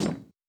Impact on Metal.wav